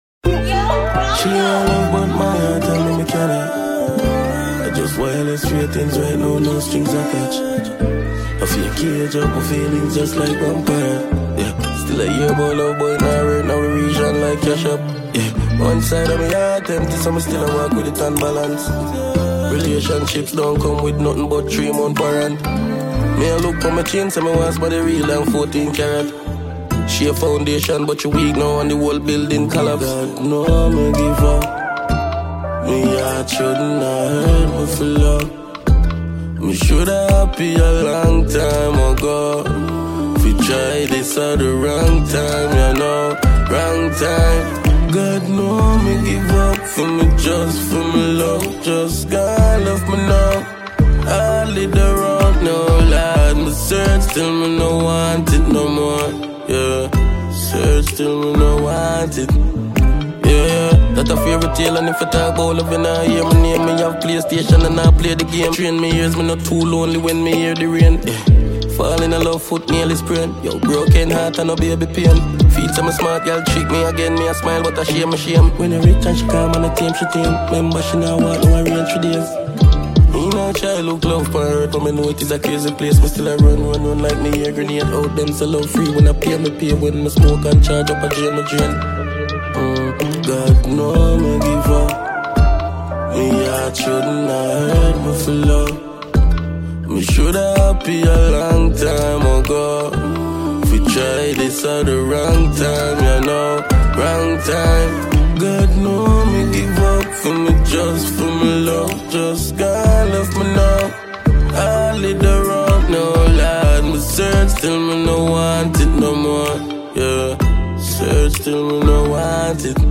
Jamaican dancehall musician